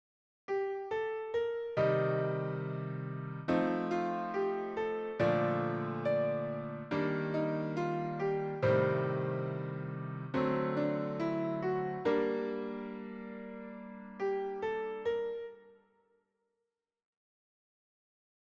Improvisation Piano Jazz